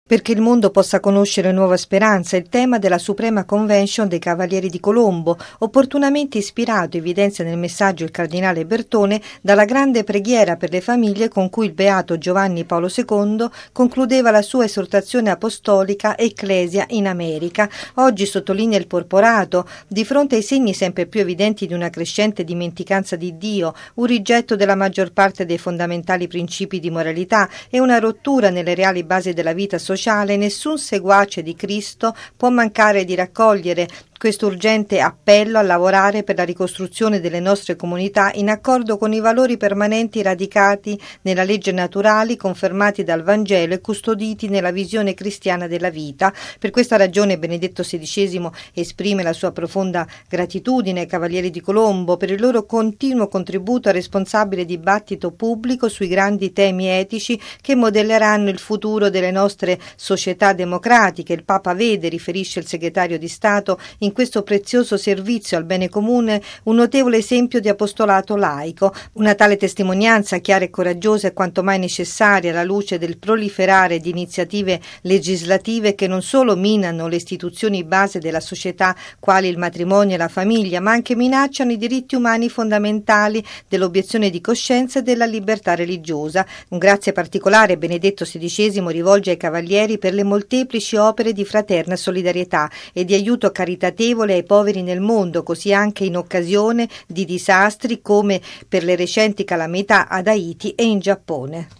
◊   Profonda soddisfazione e gratitudine per le “molteplici opere di fraterna solidarietà” esprime il Papa ai Cavalieri di Colombo, in un messaggio a firma del cardinale segretario di Stato Tarcisio Bertone, in occasione dell’Assemblea dell’Ordine in corso da ieri e fino a domani nella città statunitense di Denver, nel Colorado. Il servizio